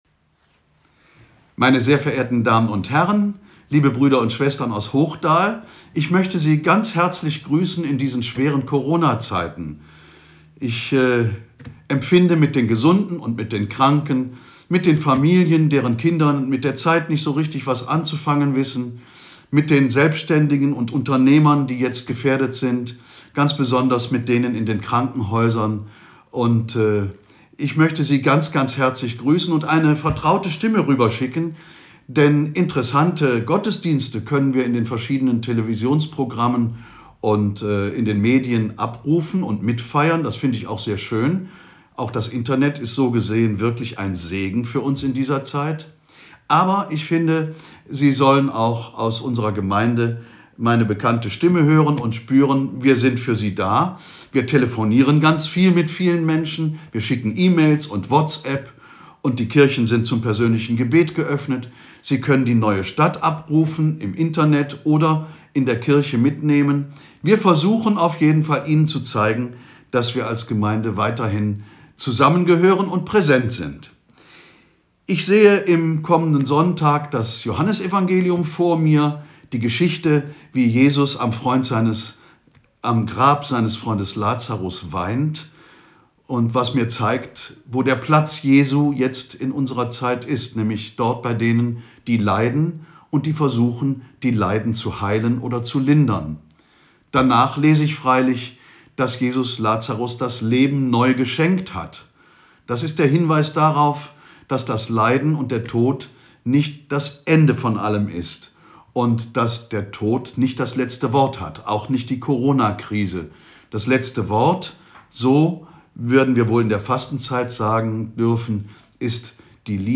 Ansprache